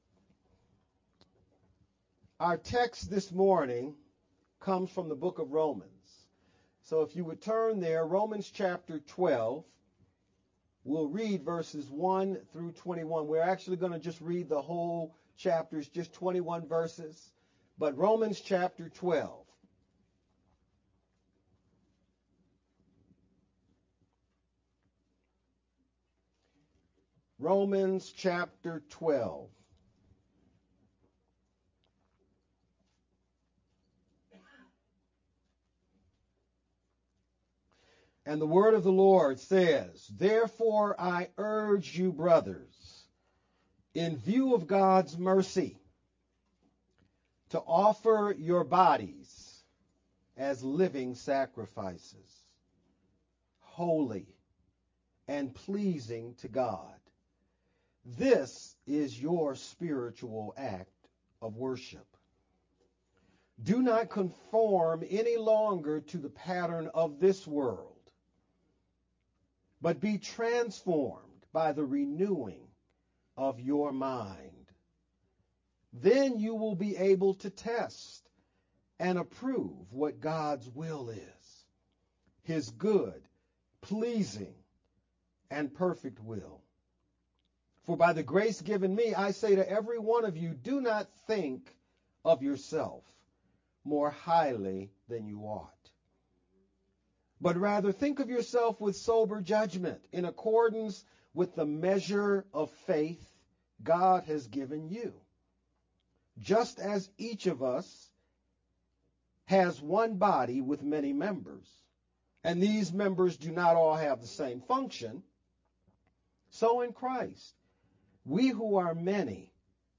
Feb-24th-Sermon-only-Made-with-Clipchamp_Converted_Converted-CD.mp3